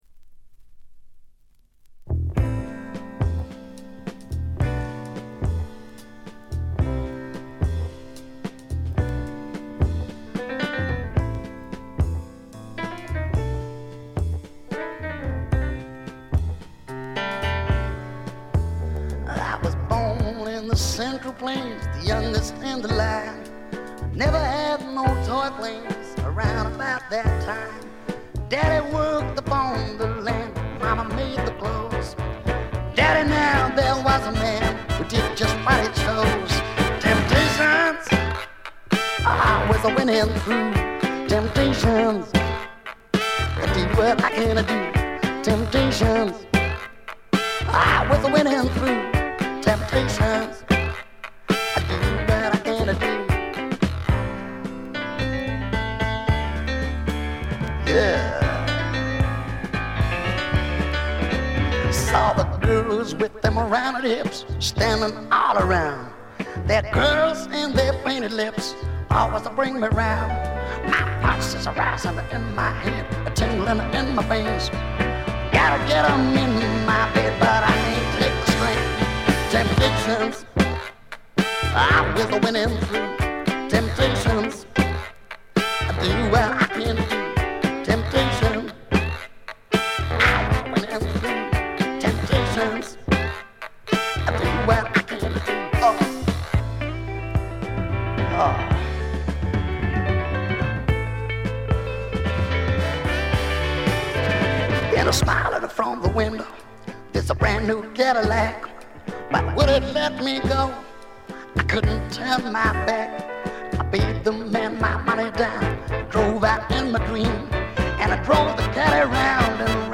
ごくわずかなノイズ感のみ。
試聴曲は現品からの取り込み音源です。
Recorded at:Nova Sounds and Island Studios
vocal and guitar
tenor sax, flute and horn arrangements